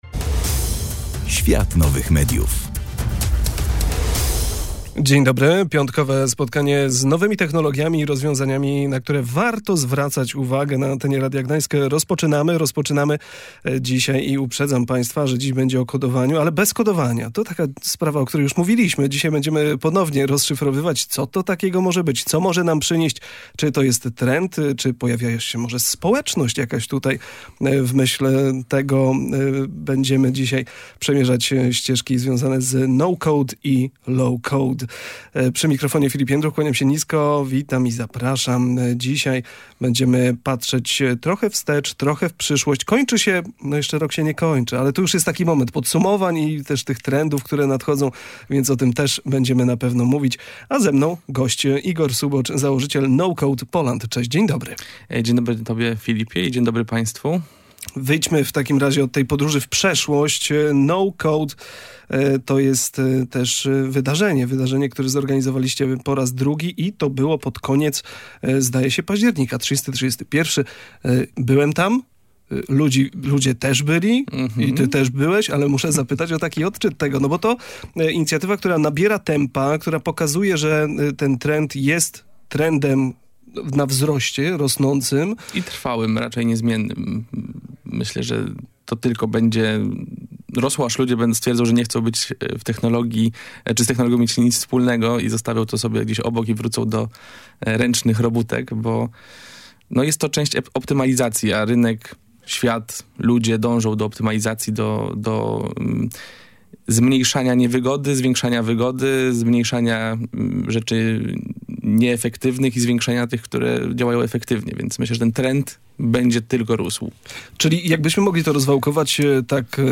W audycji „Świat Nowych Mediów” rozmawialiśmy o trendach technologicznych związanych z no-code i low-code – narzędziami umożliwiającymi tworzenie aplikacji i automatyzację procesów bez konieczności znajomości języków programowania.